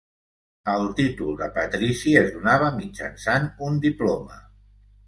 Pronounced as (IPA)
[diˈplo.mə]